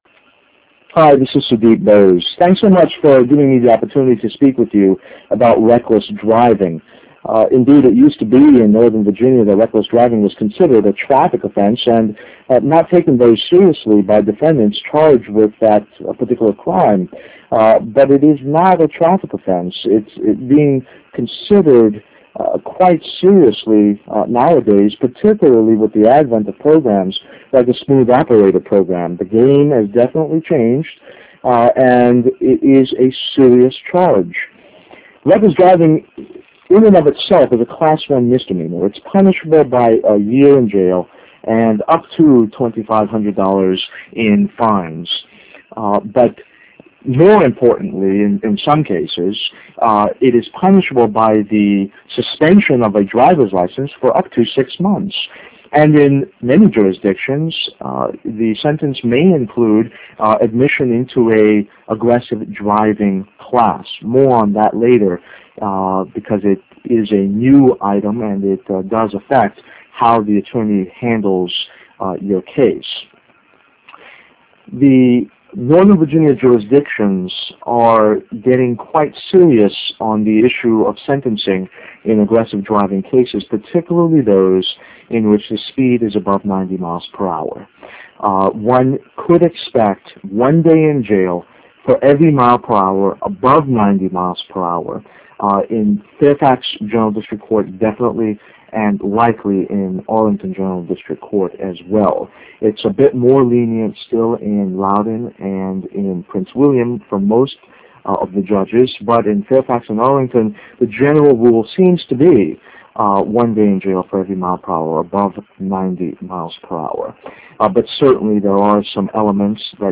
Click to hear Reckless Driving Seminar Windows Media File.